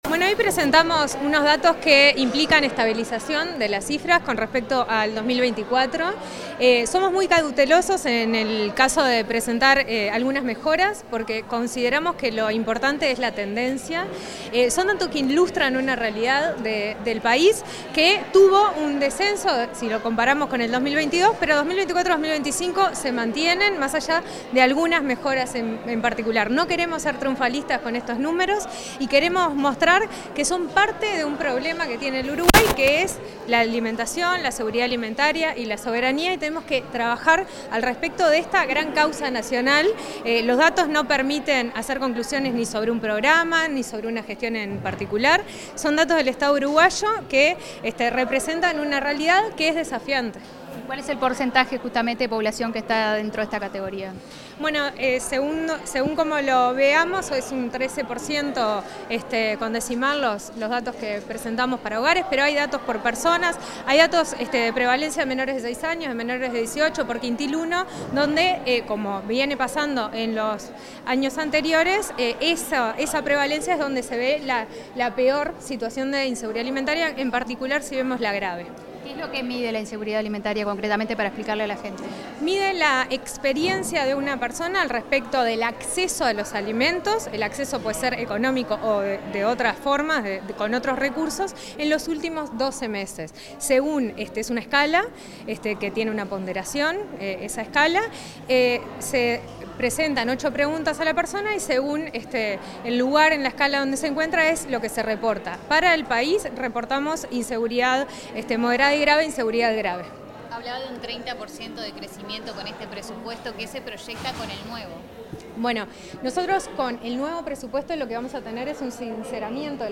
Declaraciones de la presidenta del INDA, Micaela Melgar
La titular del Instituto Nacional de Alimentación (INDA), Micaela Melgar, dialogó con la prensa tras la presentación de los avances de gestión, las